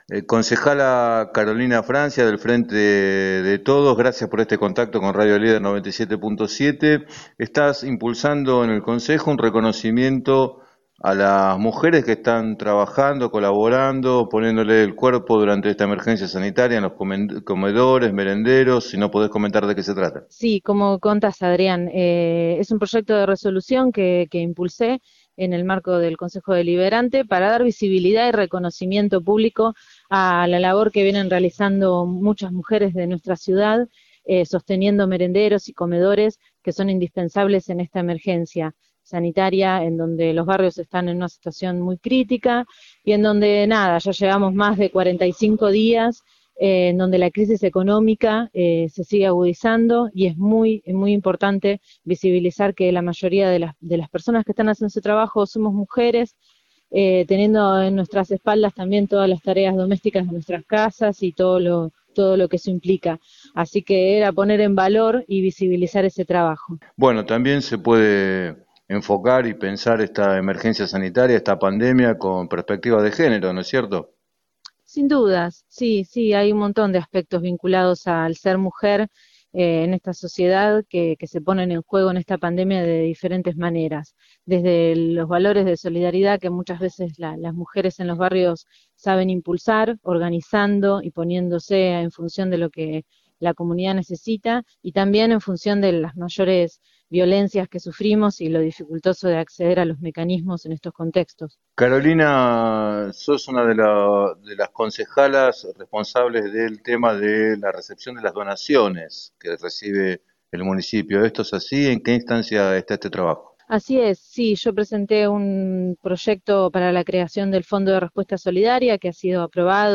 En diálogo con Radio Líder 97.7, Francia destacó la importancia de hacer visible esa tarea.